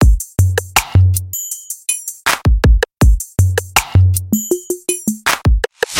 Tag: 器乐 节拍 hip_hop rap edm dubstep